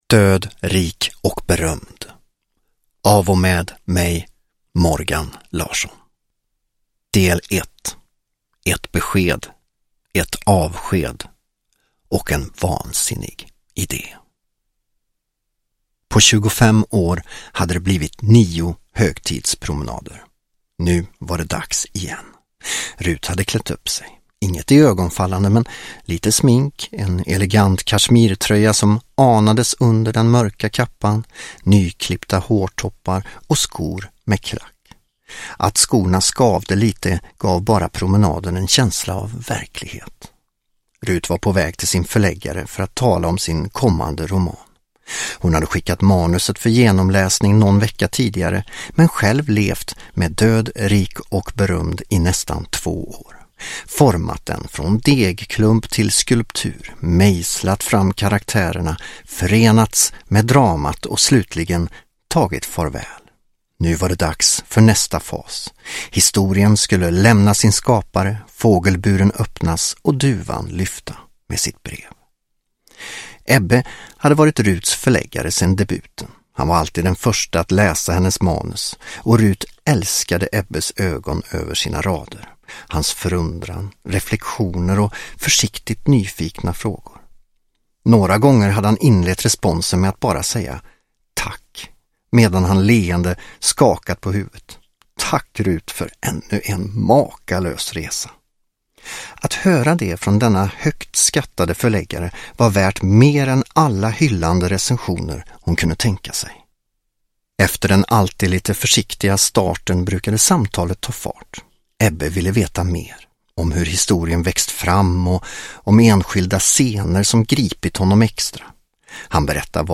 Död, rik och berömd (ljudbok) av Morgan Larsson